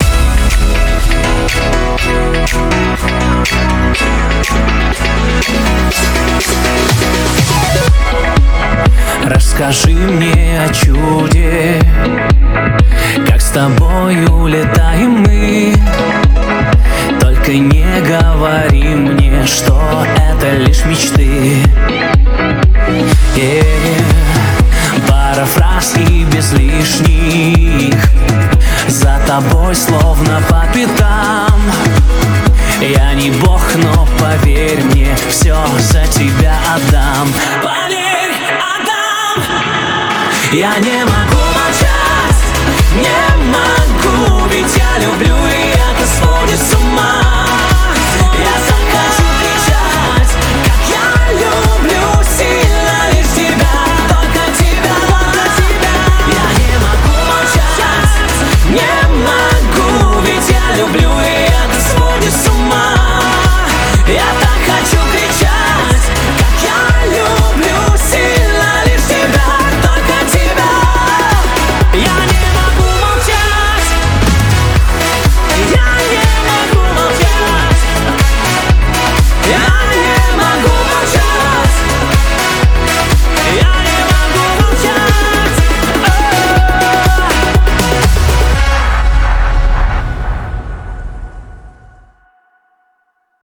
BPM122
Audio QualityMusic Cut
fits well with the happy vibe of the song.